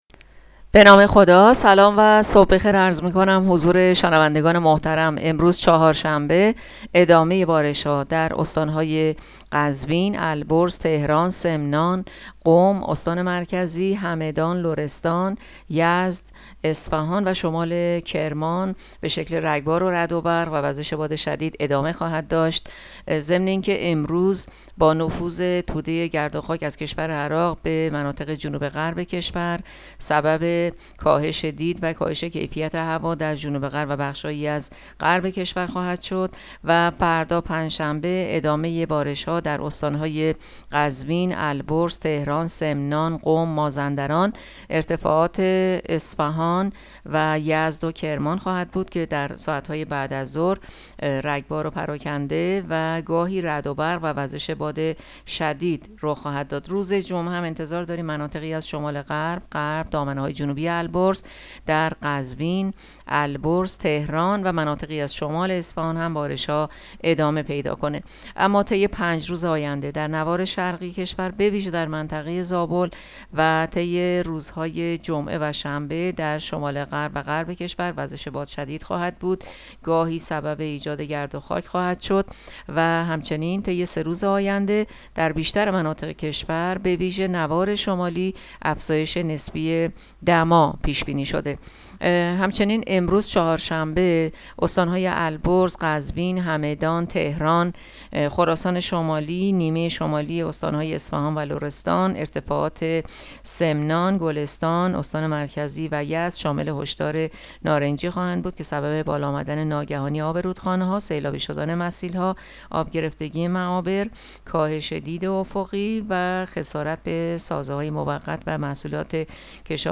گزارش رادیو اینترنتی پایگاه‌ خبری از آخرین وضعیت آب‌وهوای دهم اردیبهشت؛